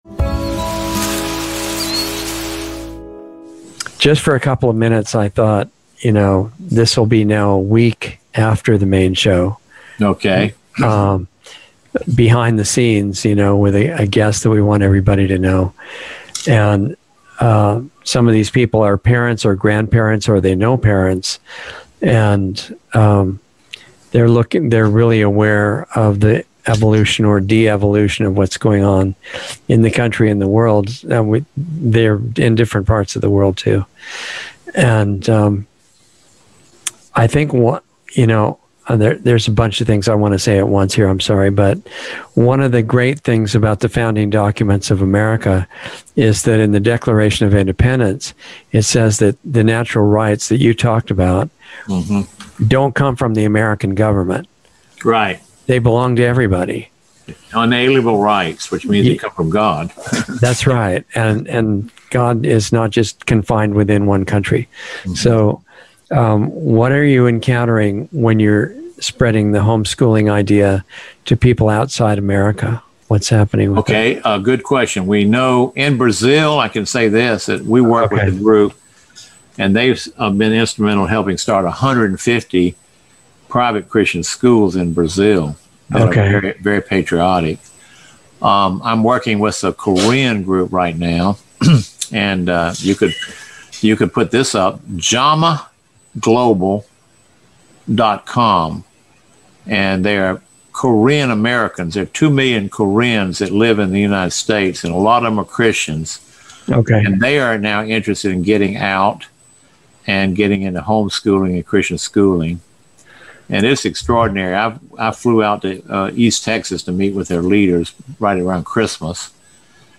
Insider Interview 6/3/21